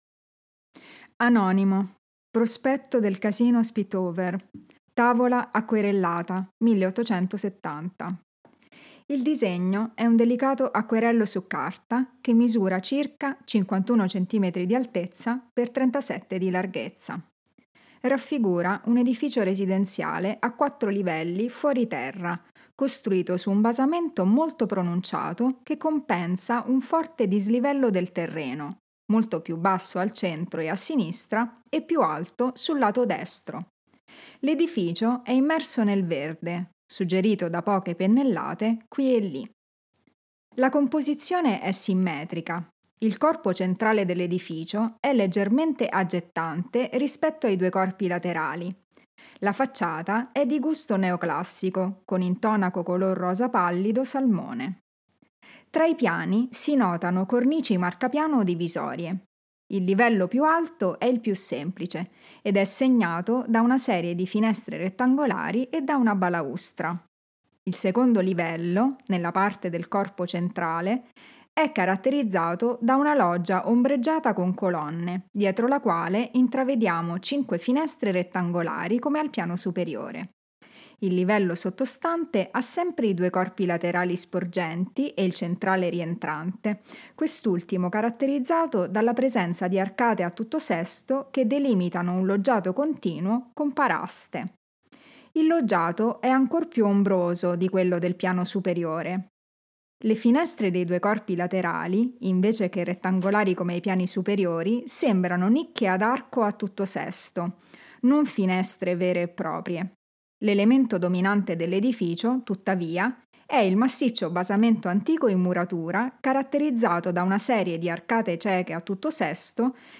Audio-descrizioni sensoriali: